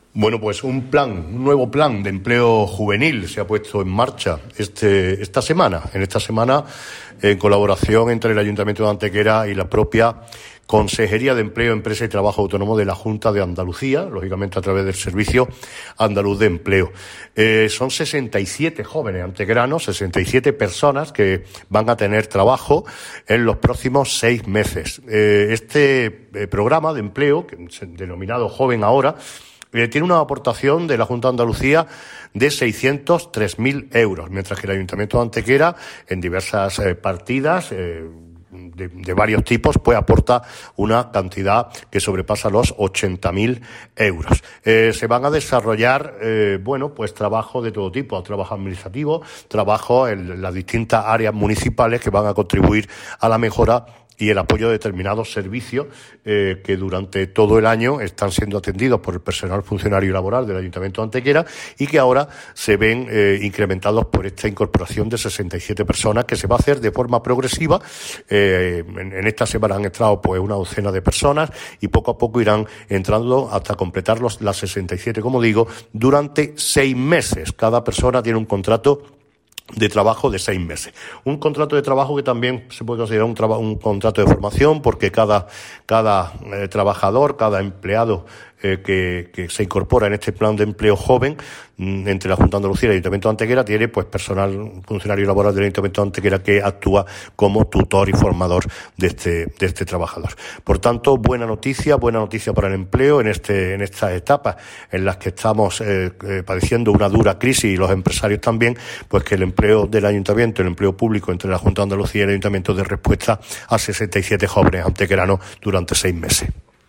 El alcalde de Antequera, Manolo Barón, y la teniente de alcalde delegada de Políticas de Empleo, Ana Cebrián, informan de la puesta en marcha en nuestra ciudad de un nuevo proyecto destinado a fomentar el empleo entre los más jóvenes: la Iniciativa para la promoción del empleo juvenil en el ámbito local, Joven Ahora.
Cortes de voz